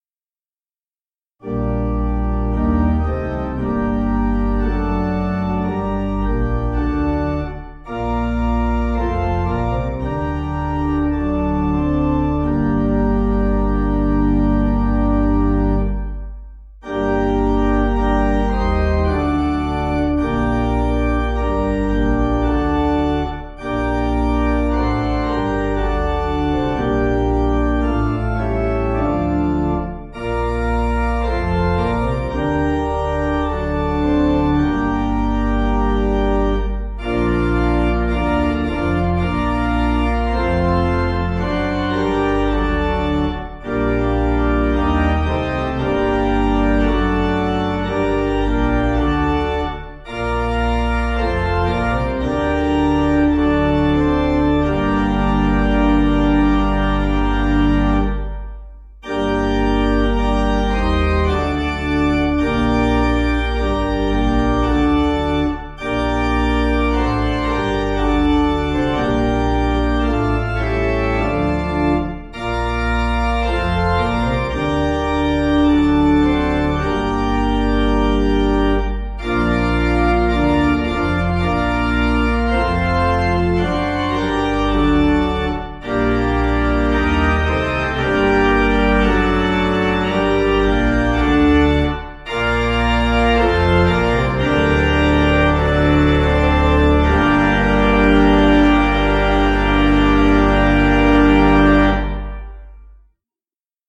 8.8.6.D
Organ